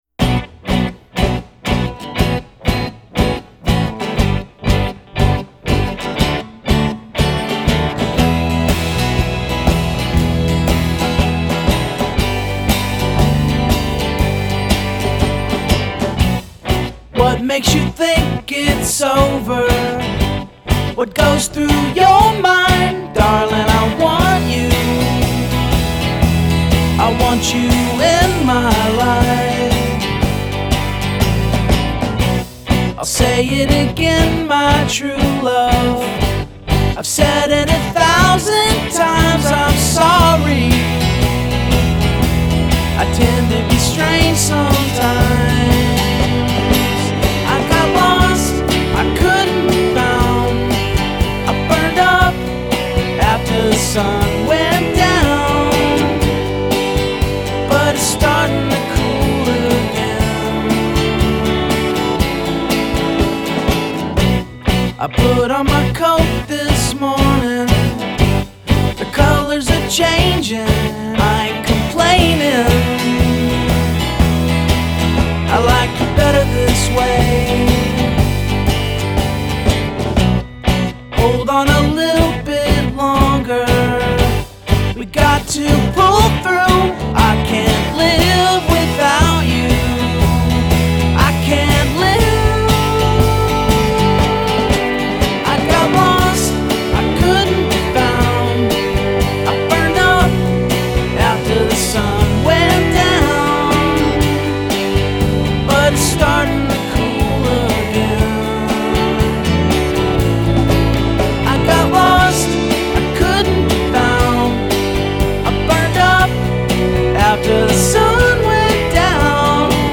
distinctive organ work